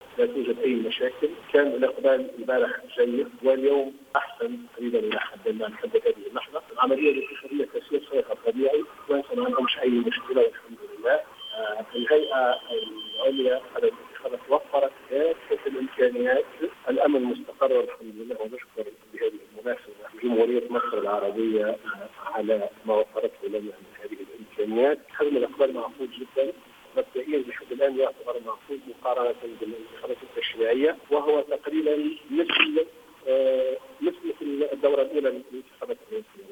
a déclaré ce samedi 20 décembre 2014 dans une intervention sur les ondes de Jawhara FM